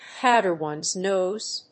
アクセントpówder one's nóse